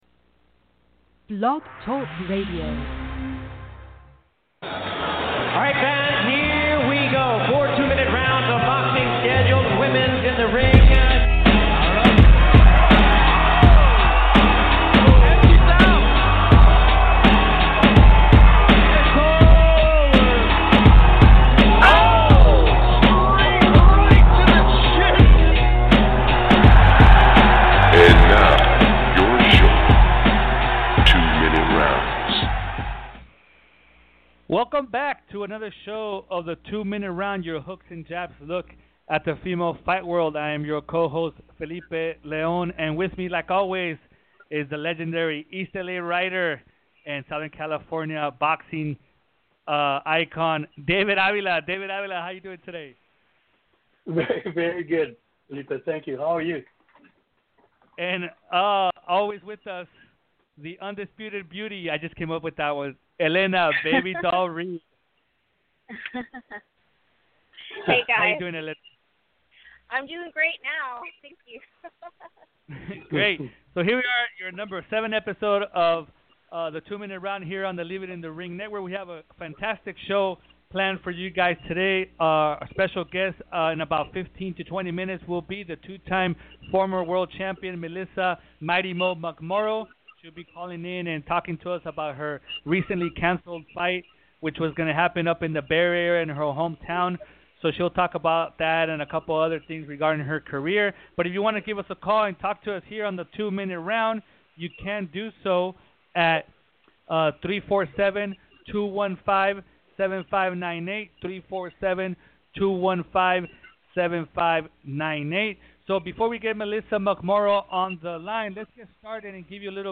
A bi-weekly podcast focused on all the news, notes, results, upcoming fights and everything in between in the wide world of female professional boxing. This week, the 2 Minute crew talks fight results, the upcoming calendar, female fight chatter ..